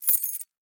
household
Keys Dropping on The Table 2